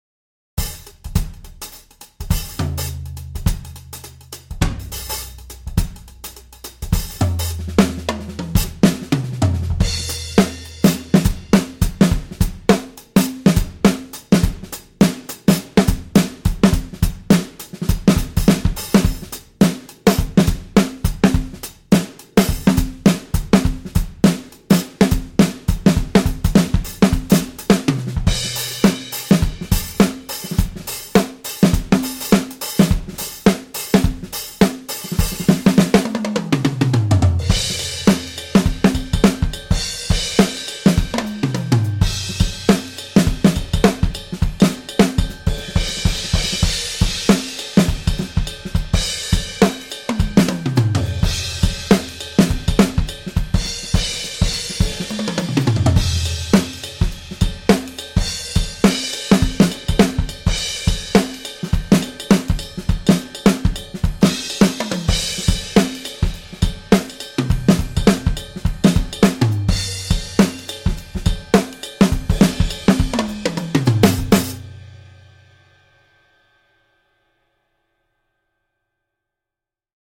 六 个原声鼓套件和超过 400 种经典鼓机声音
在英国伦敦的英国格罗夫工作室拍摄
为了增加更加有机和正确的时期风味，所有鼓和机器在数字转换之前都被记录到磁带上。